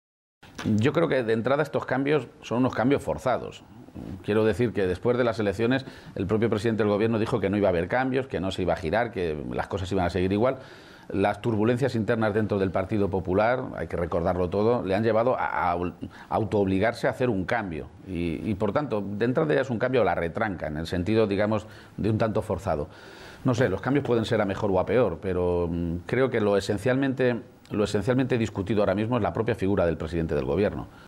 Momento de la entrevista
El candidato a la Presidencia de Castilla-La Mancha y secretario general del PSOE, Emiliano García-Page, ha sido entrevistado esta mañana en Los Desayunos de la Primera de TVE y allí ha insistido en que el principio de acuerdo que ha cerrado con Podemos en la región responde a un objetivo claro y compartido por ambas formaciones políticas: ”El principal objetivo de cualquier Gobierno responsable ahora mismo es la recuperación económica, pero no hay recuperación económica si no hay recuperación y cohesión social”.